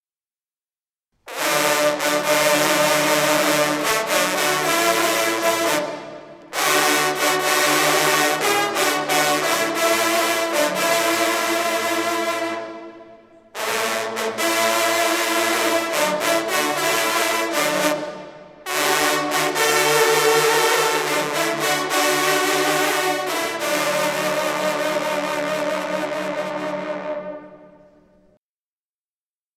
& CONCERT de PRESTIGE